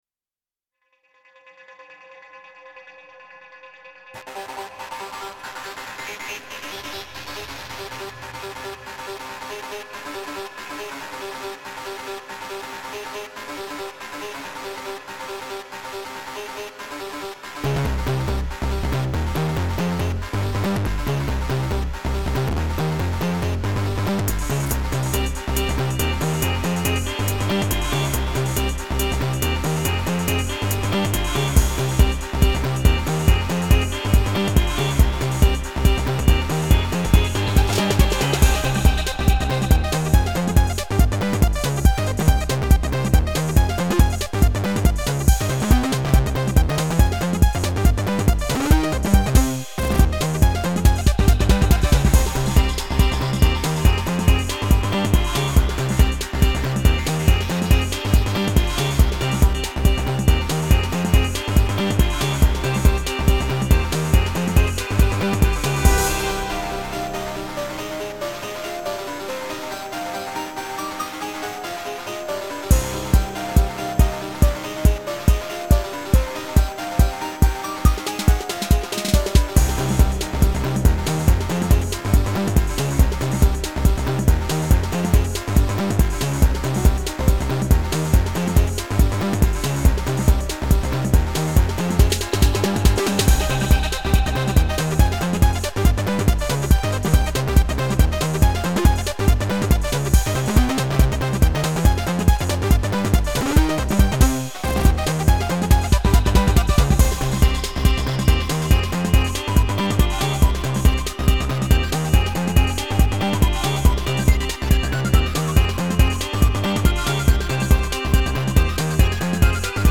[EDM] Twelve Nineteen (MP3)
:) Basically, lots of effects, for some nice sound degradation.
Cheese pours from everywhere :D Sounds like some classic tracker tune remade in a modern way, I like it!
Noticeably chiptuney, but still ornate enough for consideration as EDM.
certainly very aggressive for you.
The tuned buried in the distortion and minor mode is still bouncy and happy somehow.
I think the swing lifts it a bit.